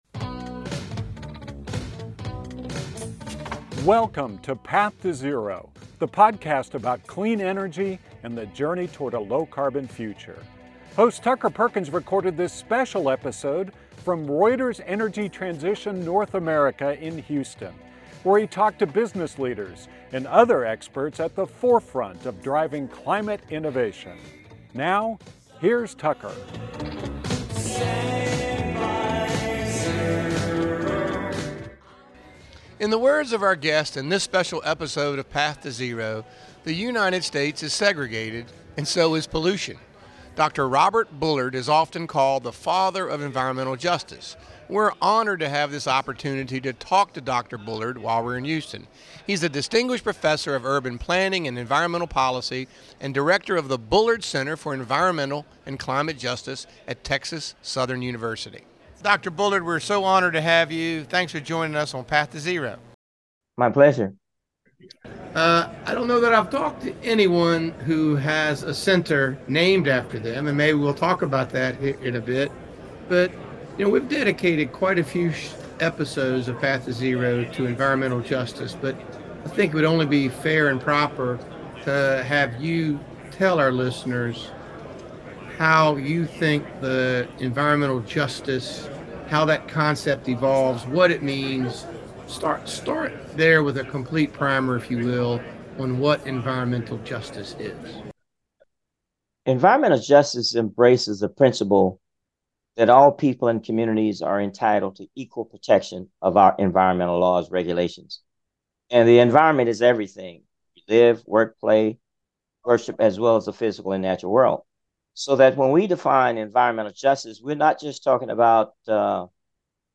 Path to Zero kicks off a series of interviews recorded from Reuters Energy Transition North American in Houston with a special episode focused on environmental justice.